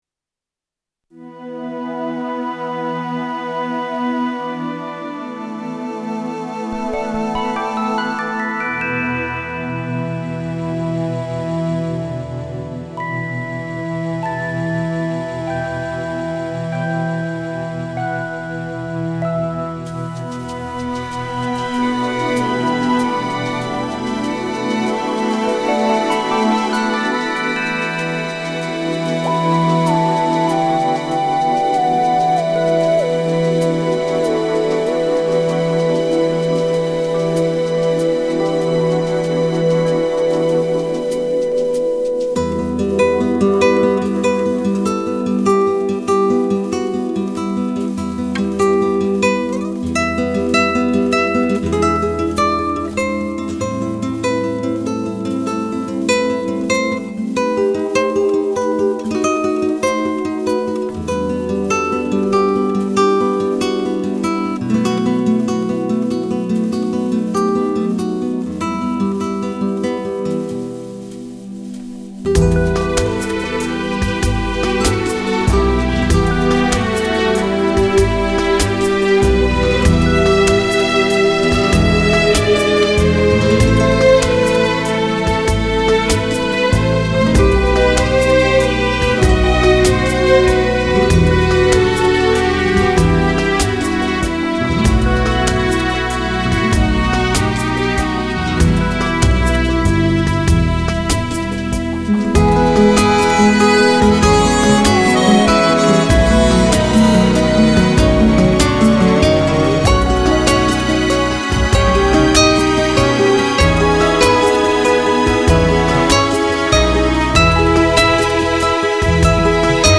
романс неизвестный (открыта)